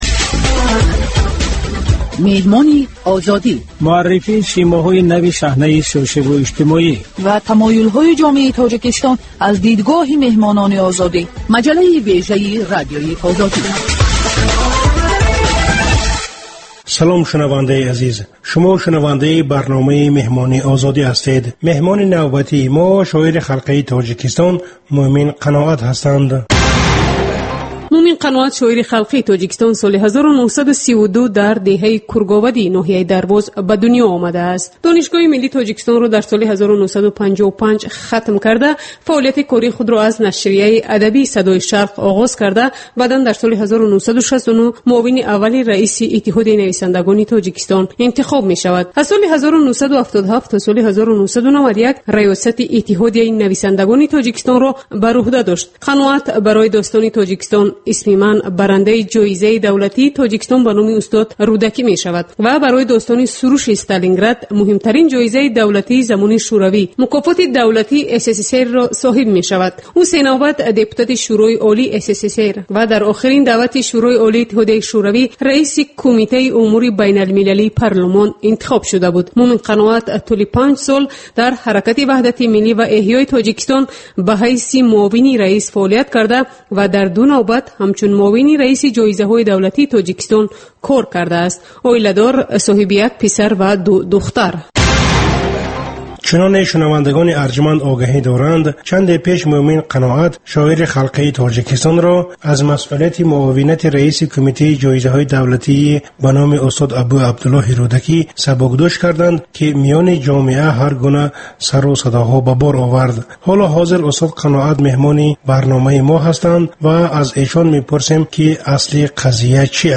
Гуфтугӯи ошкоро бо чеҳраҳои саршинос, намояндагони риштаҳои гуногун бо пурсишҳои ғайриодӣ.